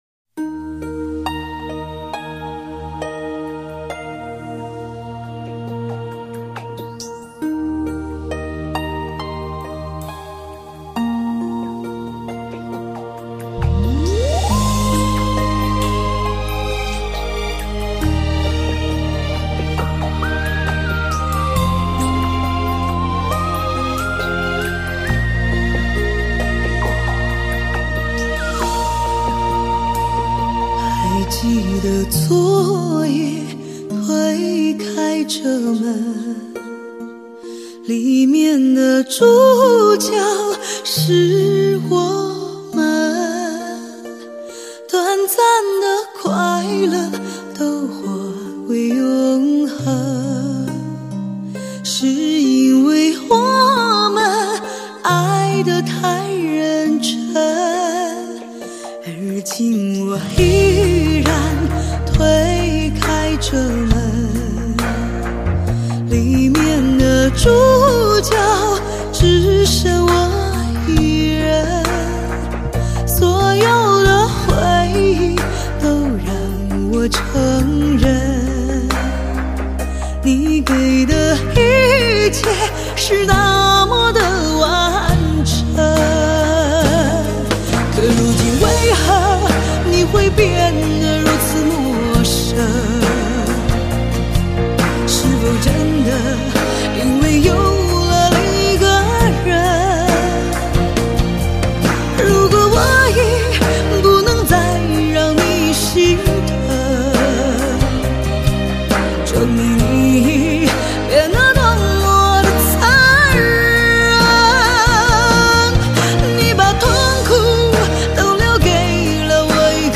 看不透的红尘伤感
富有磁性的嗓音在歌坛上确立了自己独特的音乐风格